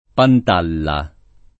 [ pant # lla ]